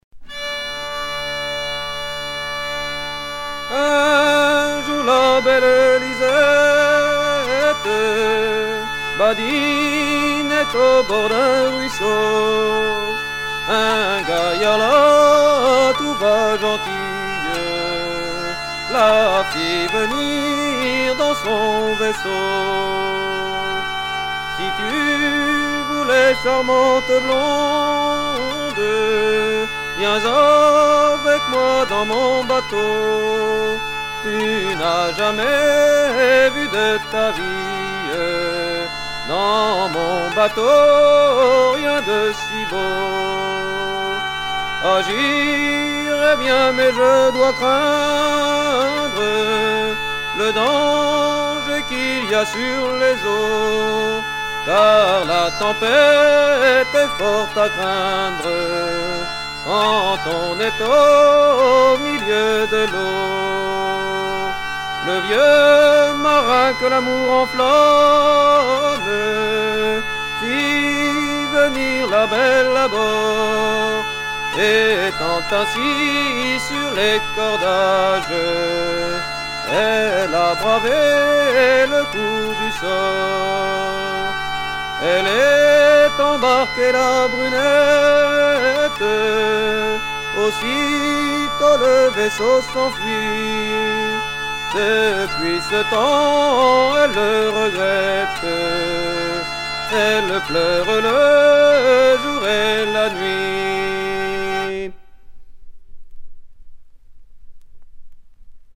Version recueillie en 1960 auprès d'un chanteur anonyme d'Ocqueville
Genre strophique